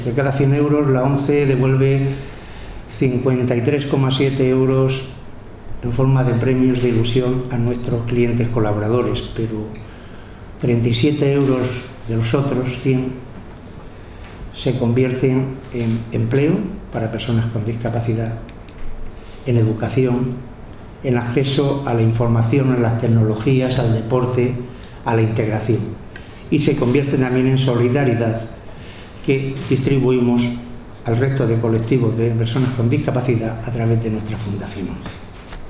Una jornada de HISPACOOP sobre juego responsable en la sede de la ONCE repasa los retos que afronta este compromiso y acoge la presentación del último proyecto de investigación sobre esta materia galardonado por la Organización